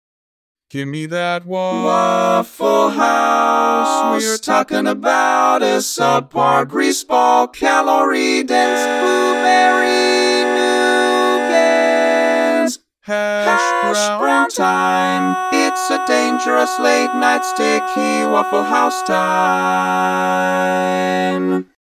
Key written in: F# Major
How many parts: 4
Type: Barbershop
All Parts mix: